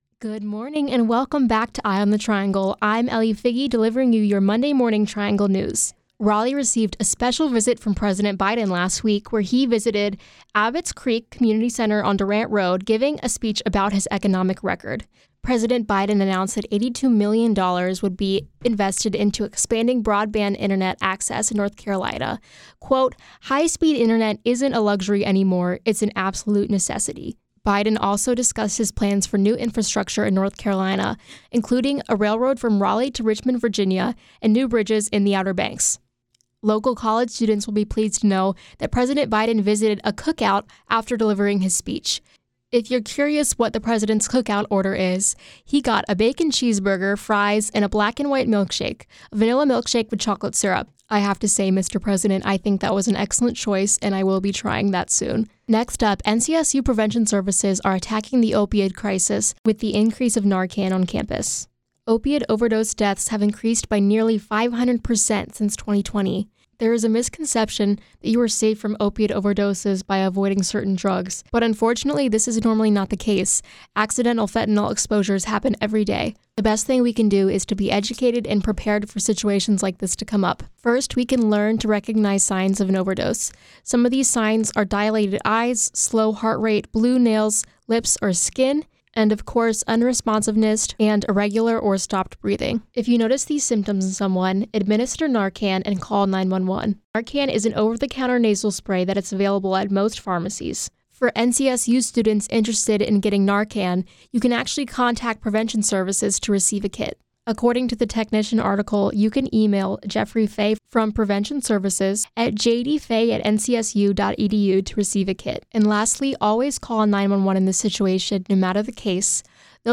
WKNC_CMA2024_AudioNewscast.mp3